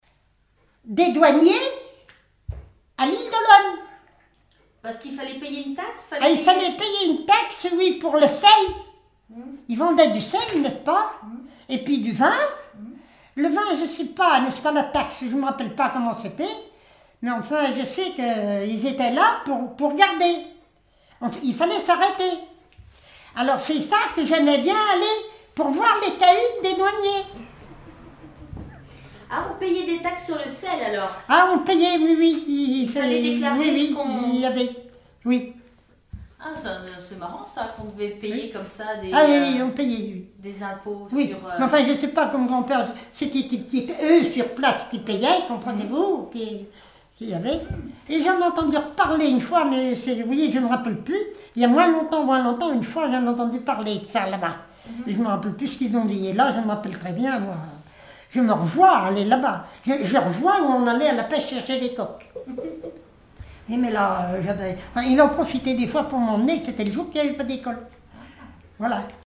Témoignages sur la vie domestique
Catégorie Témoignage